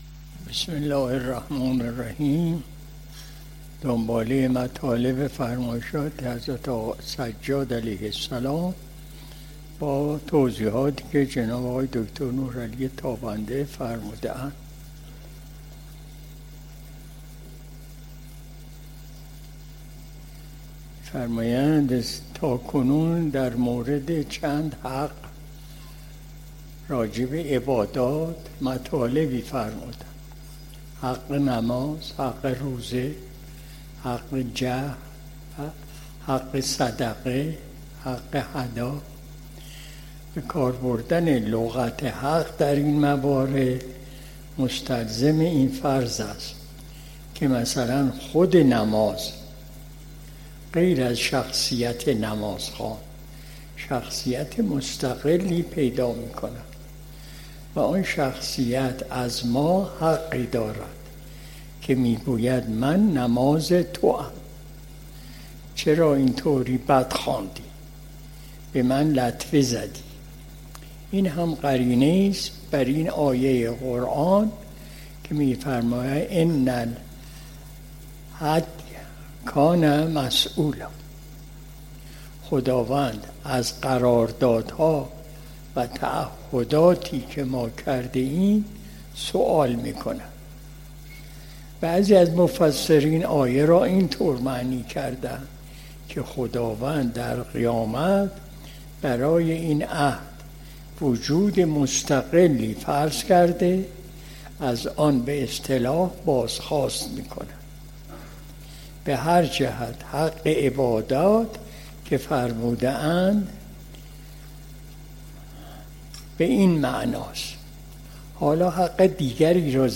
مجلس شب جمعه ۱۹ مرداد ماه ۱۴۰۲ شمسی